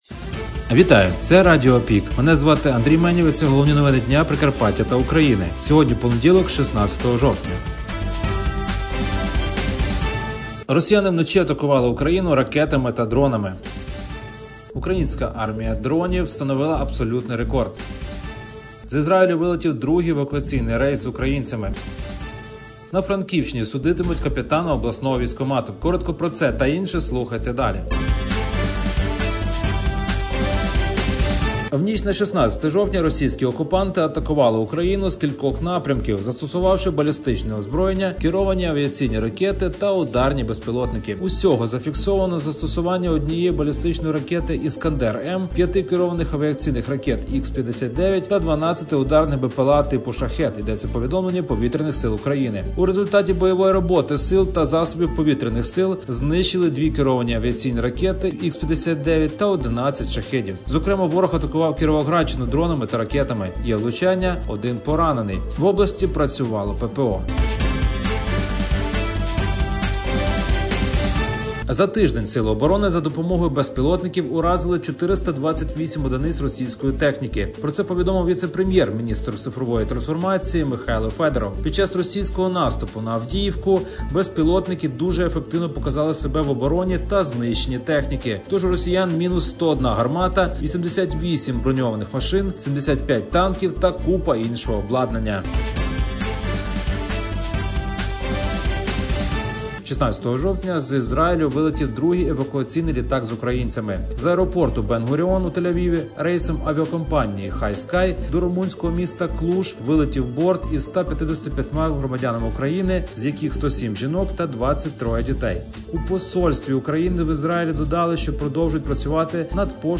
Радіо ПІК: головні новини Прикарпаття та України за 16 жовтня (ПРОСЛУХАТИ)